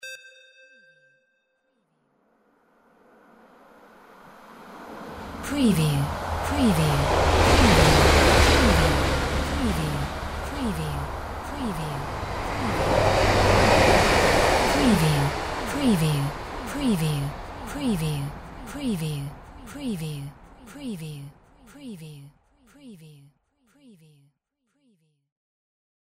Train small passby 02b
Stereo sound effect - Wav.16 bit/44.1 KHz and Mp3 128 Kbps
previewTRAN_TRAIN_SMALL_PASSBY_WBHD02B.mp3